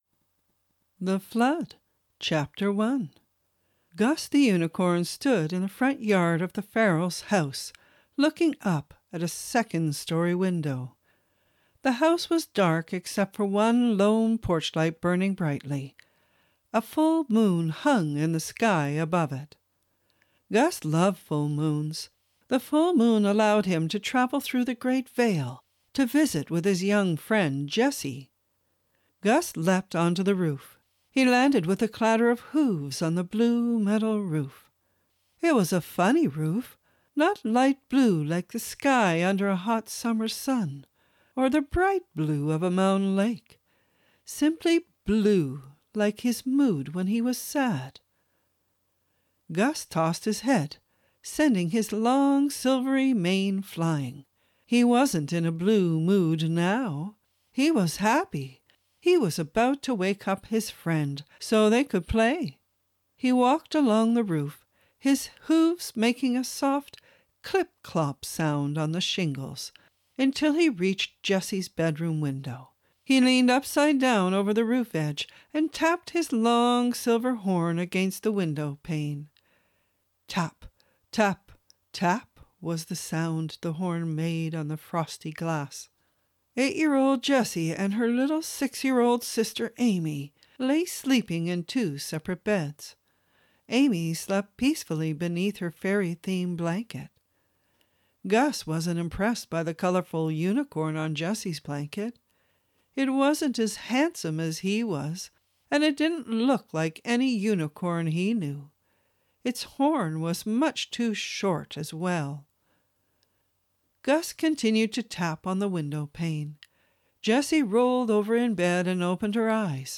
This is what started me reading these stories in the play format so that the younger and older children as well as those with learning disabilities could follow along.